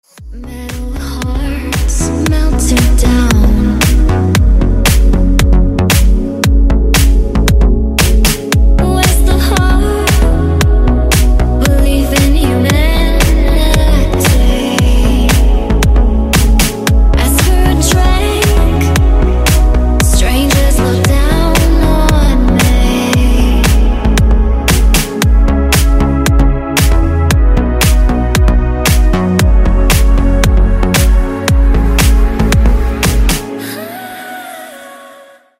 • Качество: 128, Stereo
deep house
Electronic
спокойные
красивая мелодия
nu disco
красивый женский голос
Indie Dance